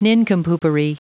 Prononciation du mot nincompoopery en anglais (fichier audio)
Prononciation du mot : nincompoopery